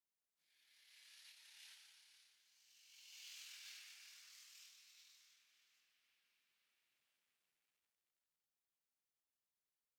latest / assets / minecraft / sounds / block / sand / sand5.ogg
sand5.ogg